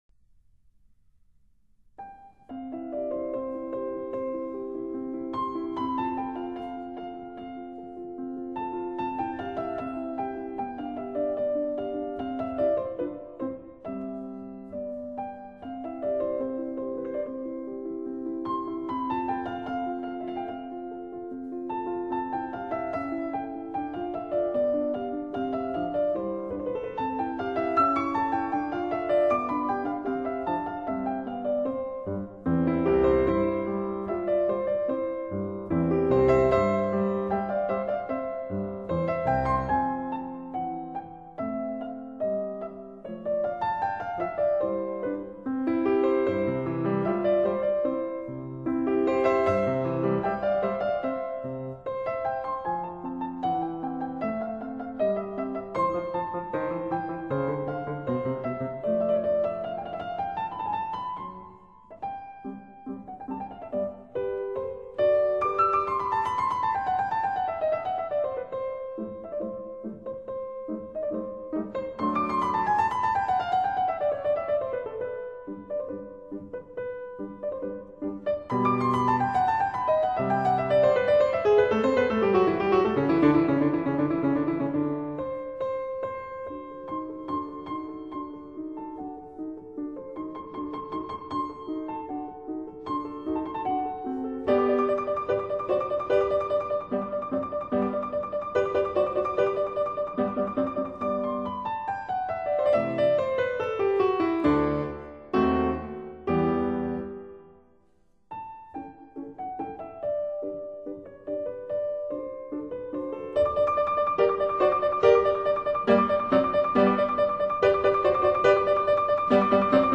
她的演奏继承的是极其典型、正统的维也纳学派。
这种坦率的固执己见，或者说“保守主义”的态度，使得她的演奏听起来严谨慎重，但又不乏明晰优雅的轻松气质。